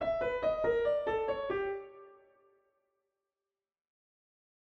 The main eight-note motif
played on piano